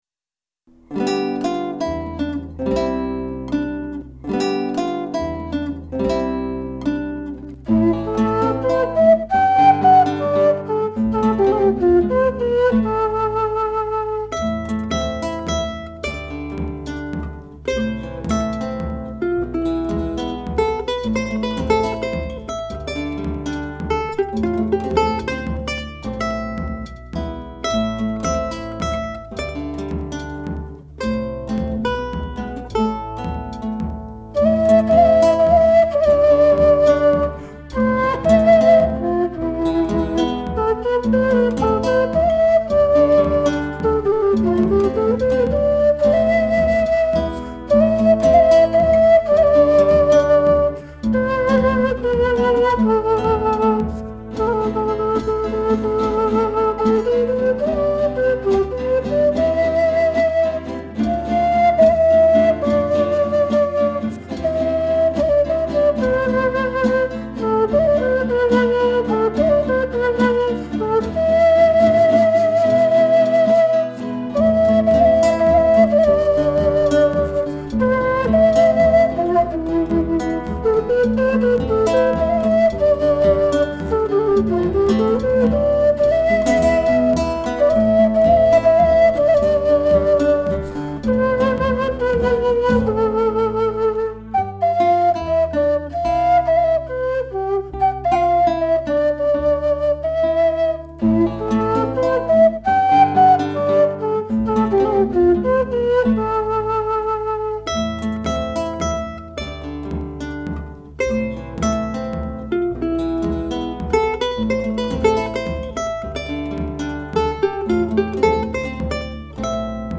別々に録音した楽器やパソコンで作ったパーカッションをミックスして完成します。
スタジオは鍼灸院の待合室です。